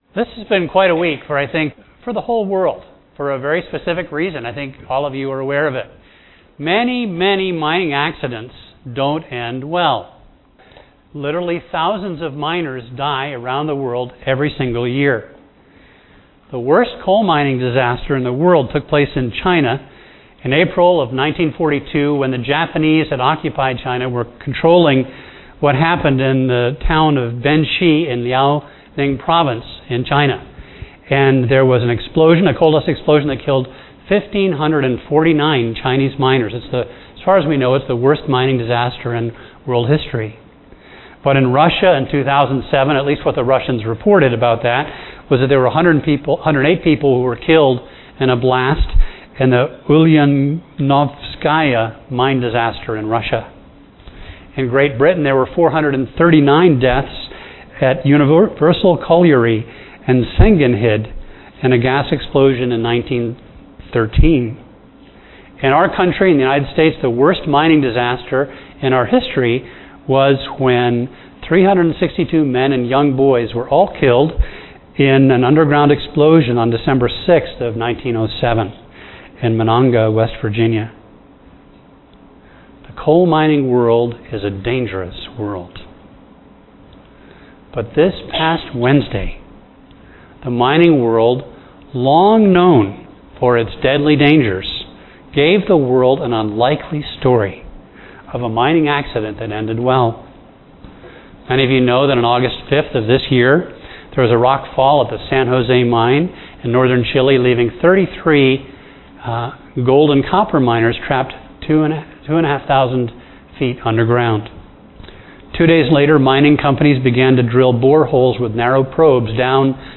A message from the series "The Acts."